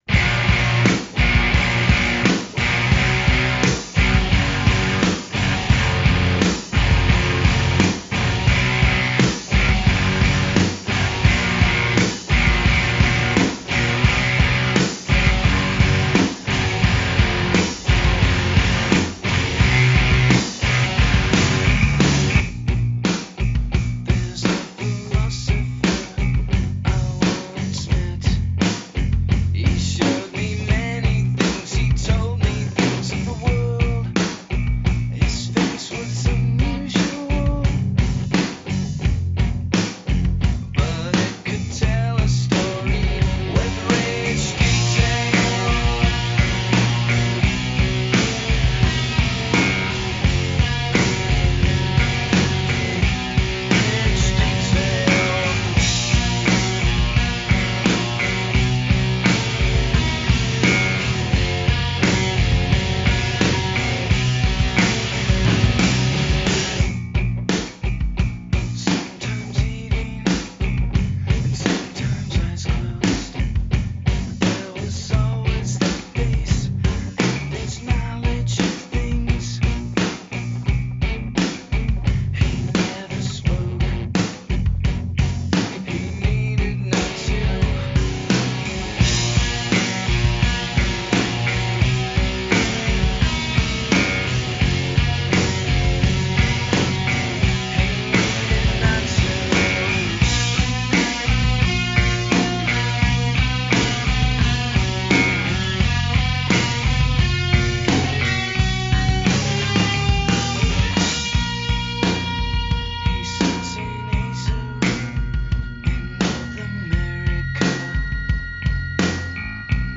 It's so dissonant and weird